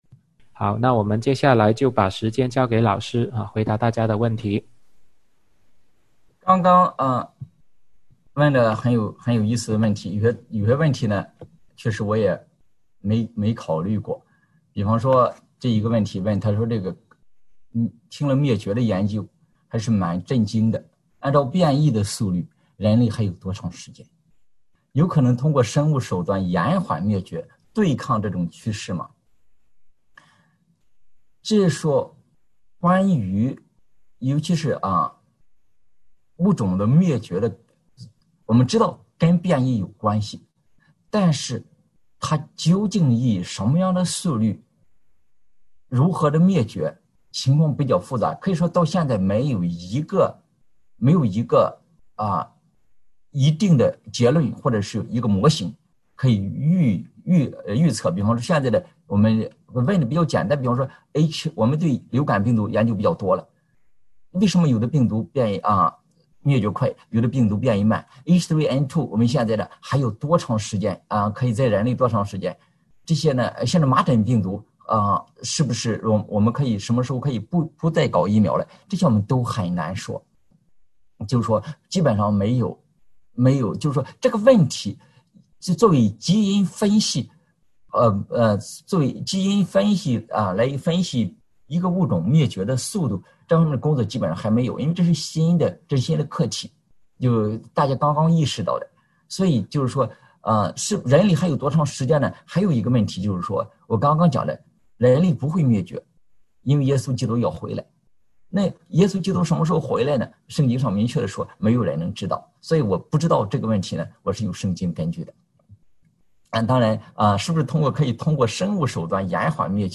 《病毒的演变》讲座直播回放